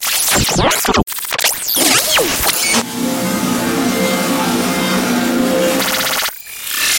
Звуки глитч-эффектов